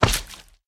sounds / mob / slime / big3.ogg